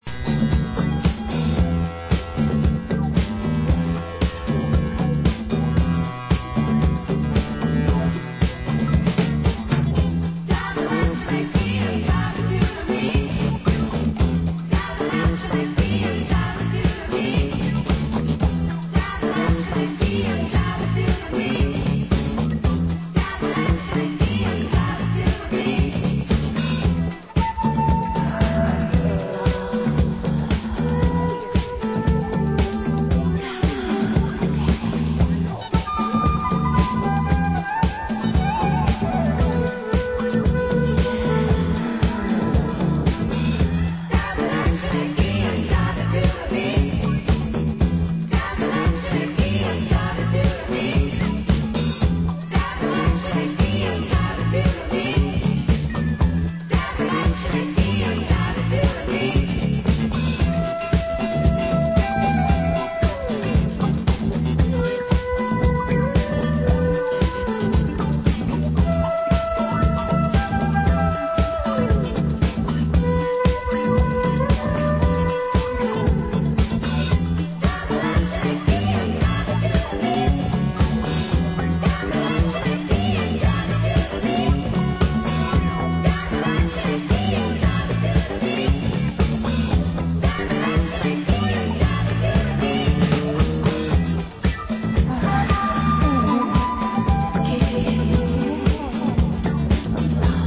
Genre: Disco